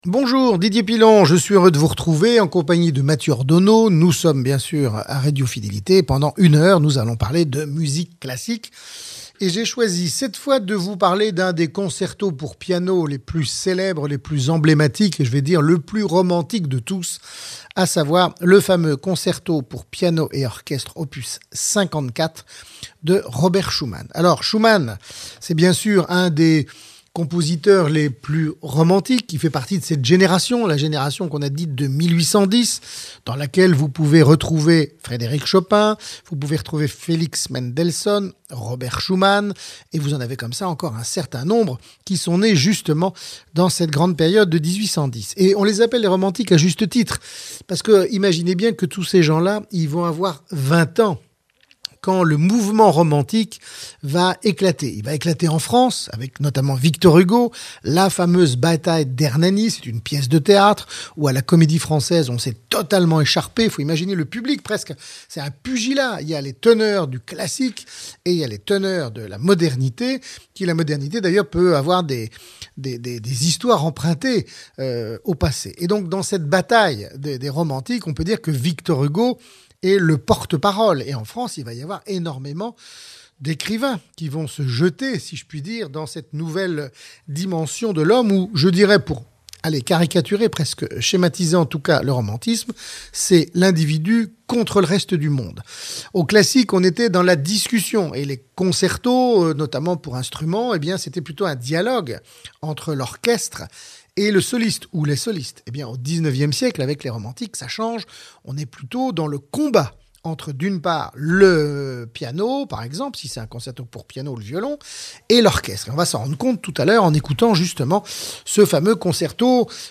DP-Schumann - Concerto pour piano et orchestre